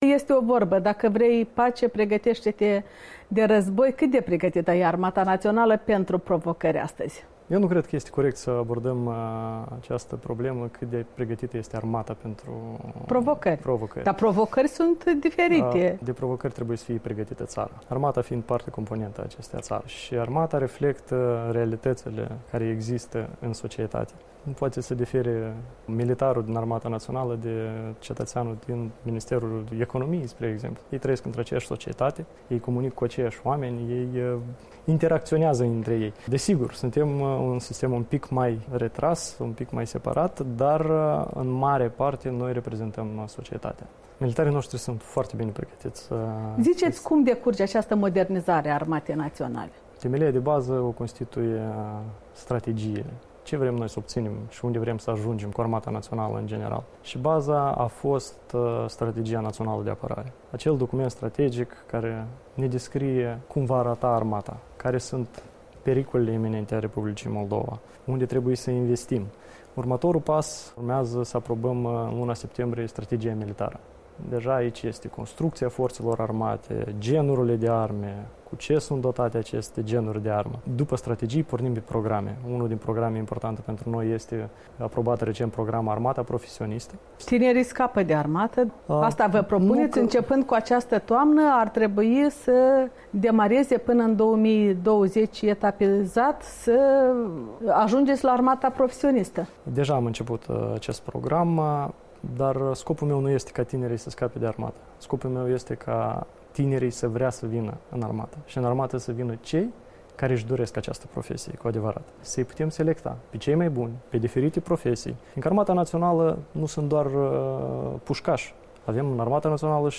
Interviu cu ministrul moldovean al apărării Eugen Sturza despre starea Armatei Naționale.